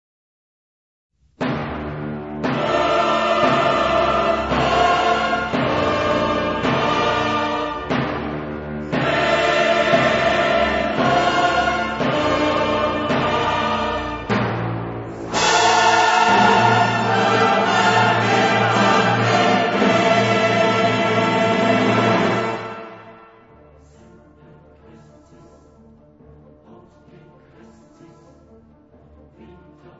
• arie
• musica classica